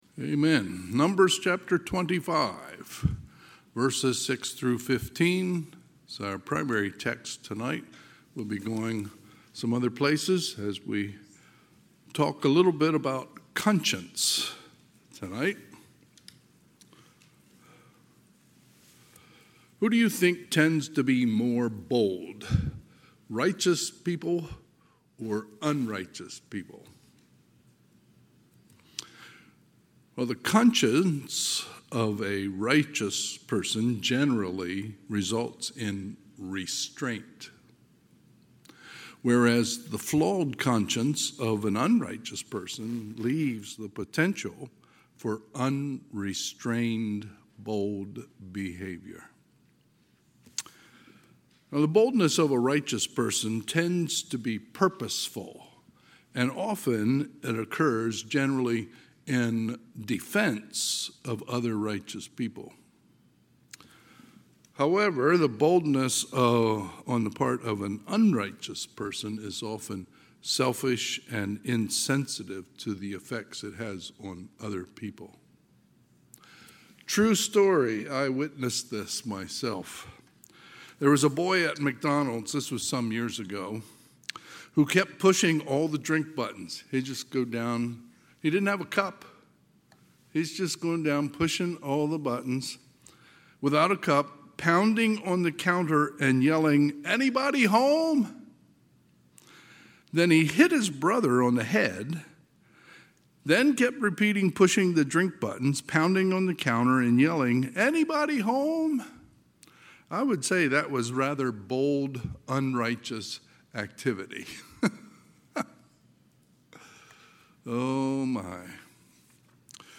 Sunday, July 27, 2025 – Sunday PM